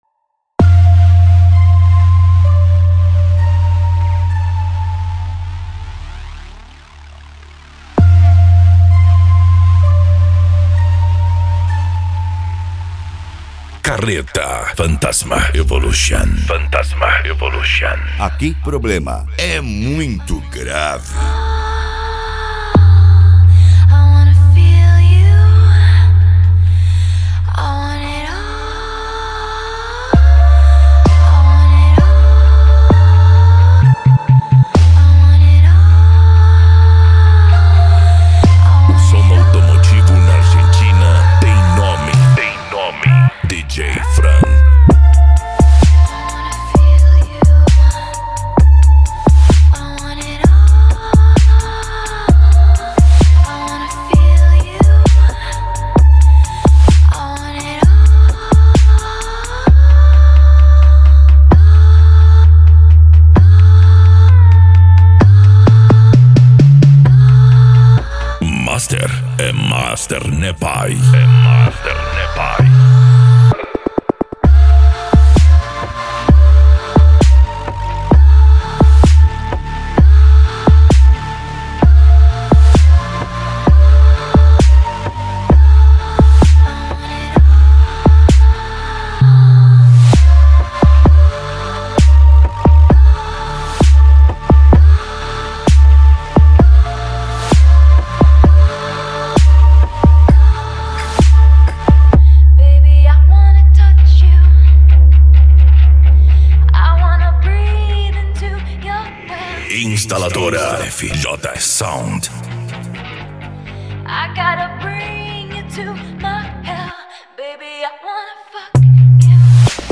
Bass
Deep House
Eletronica
Mega Funk